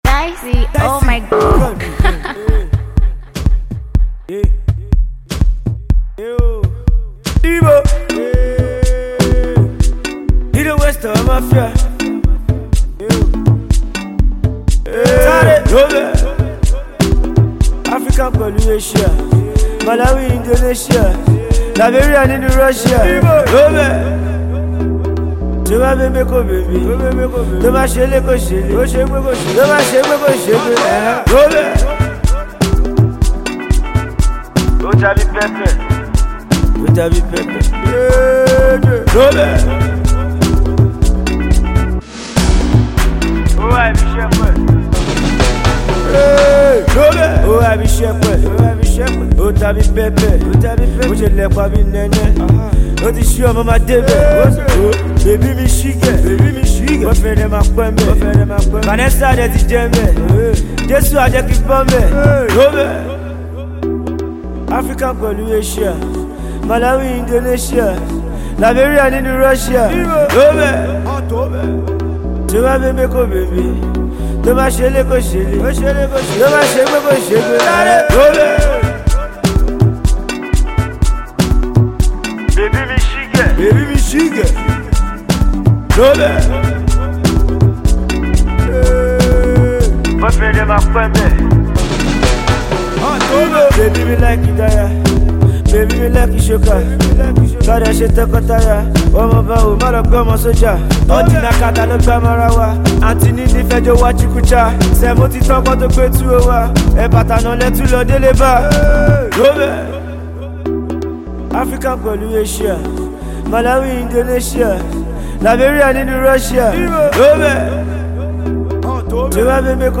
Afro-pop
Zanku inspired record
contemporary street pop
a very potent and appealing vibe